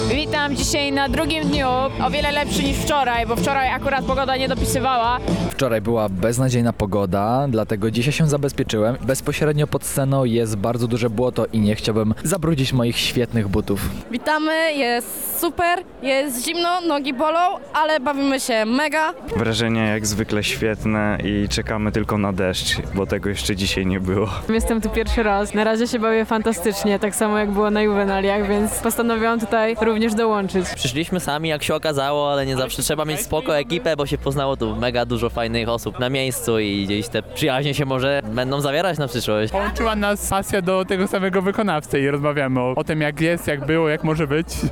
O swoich wrażeniach najlepiej powiedzą nam jednak uczestnicy imprezy:
Lublinalia, relacja
ogolna-relacja.mp3